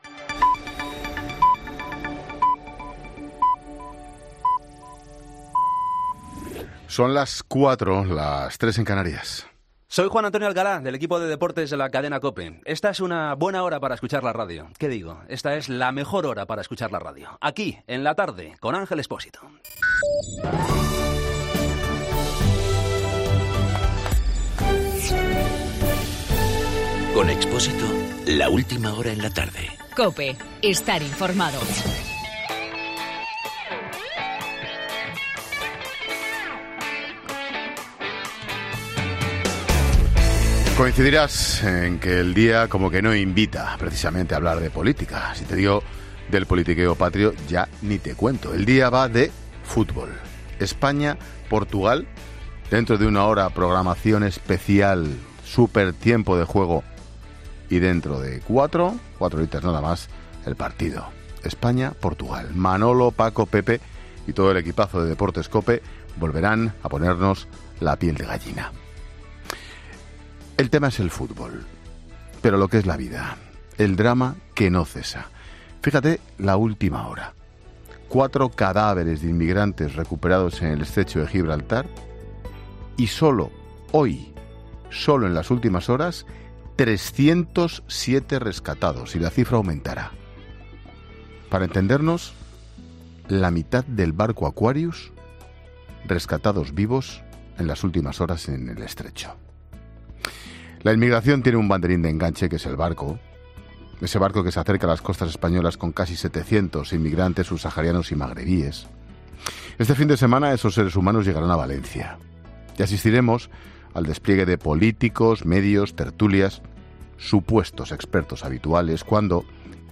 Ángel Expósito en su monólogo de este viernes ha repasado la actualidad del día, donde prima la llegada del buque Aquarius a Valencia, la Selección Española, y la reapertura de las embajadas por parte de la Generalitat: "Sin duda alguna el fútbol es el tema. Pero a la vez... lo que es la vida...el drama que no cesa.